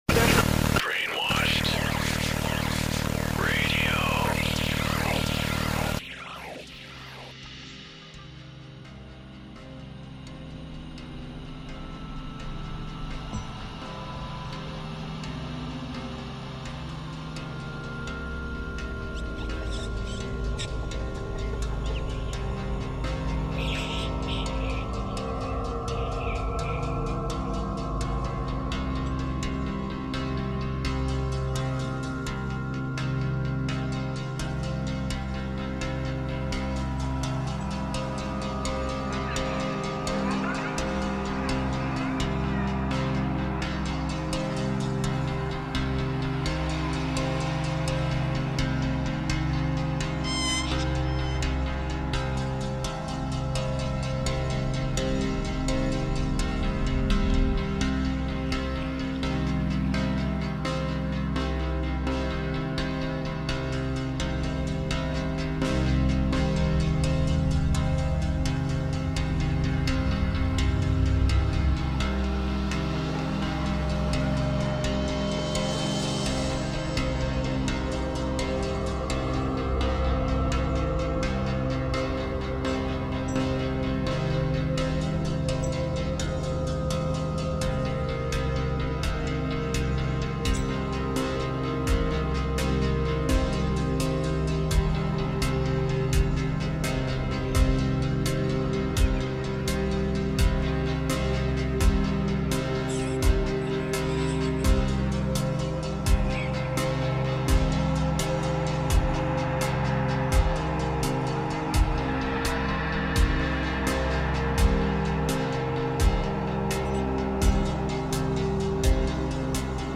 Ecclectic music styles featuring artists